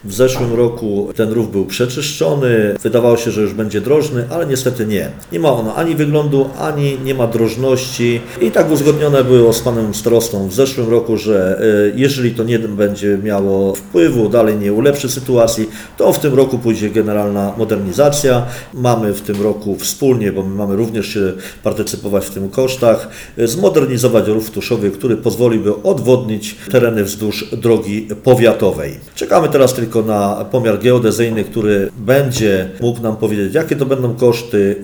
Jak tłumaczy wójt gminy Andrzej Głaz każdy ulewny deszcz powoduje podtopienia podwórek i piwnic. Obecna przepustowość rowu jest zbyt mała i nie zdaje egzaminu – zaznacza wójt gminy.